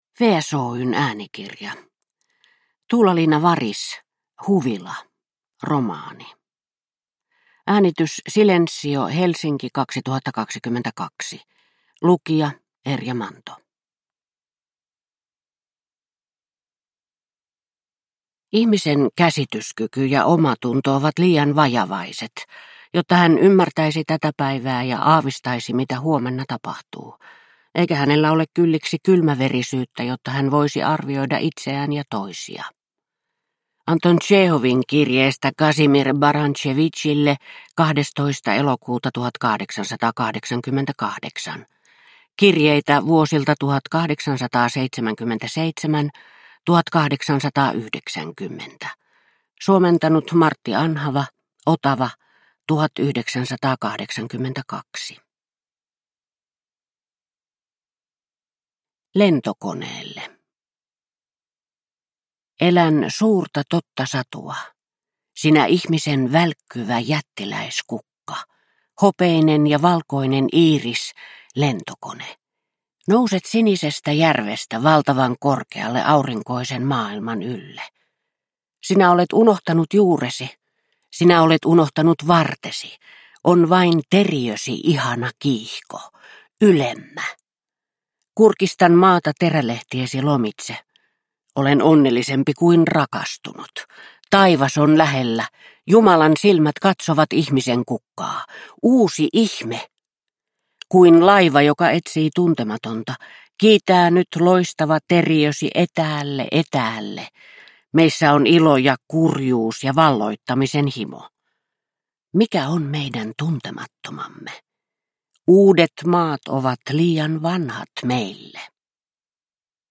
Huvila – Ljudbok – Laddas ner